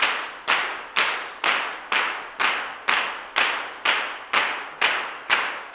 125GCLAPS1-L.wav